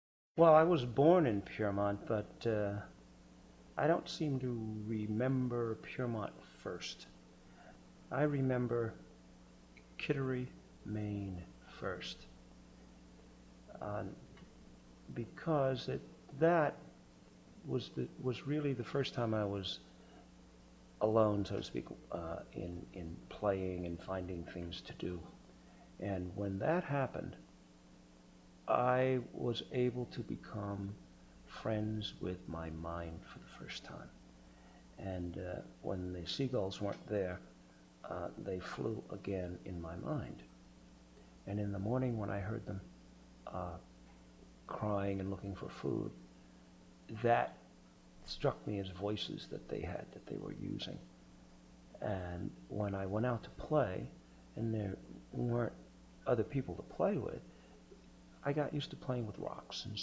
Excerpts from Interviews with Arthur Cunningham